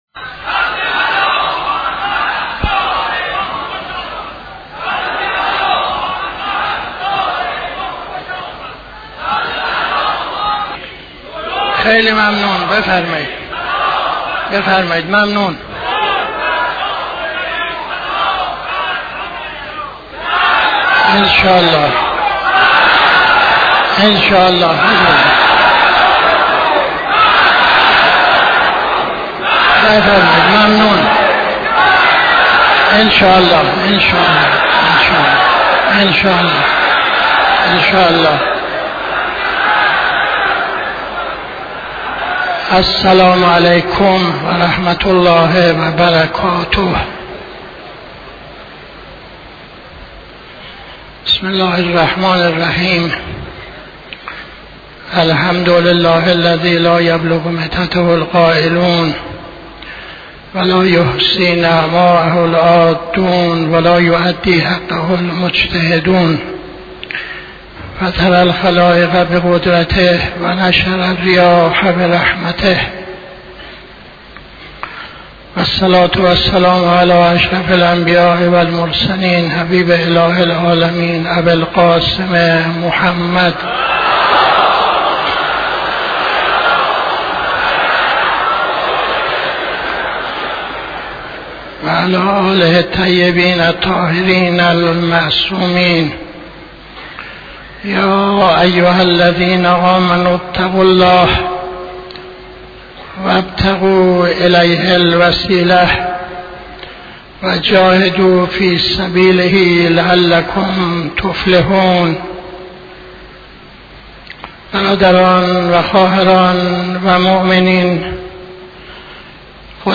خطبه اول نماز جمعه 13-10-81